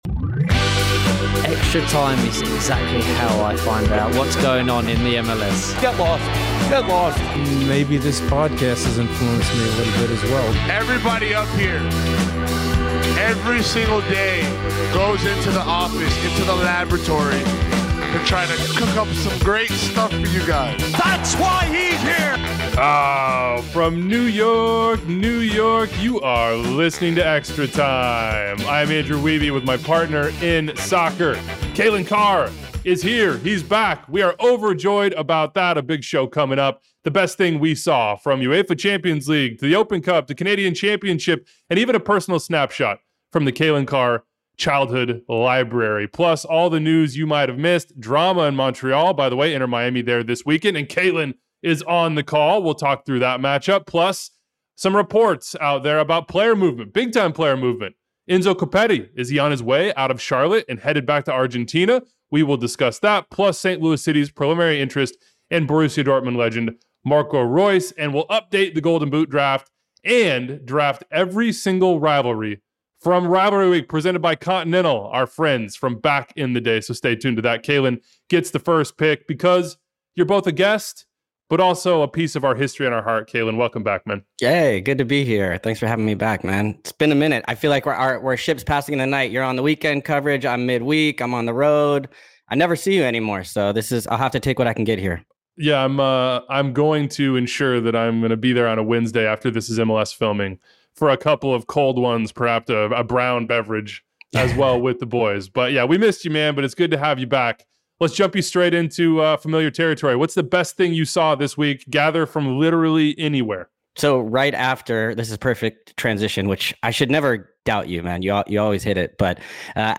Wilfried Nancy interview! Beating Monterrey, inspiring belief and his own career aspirations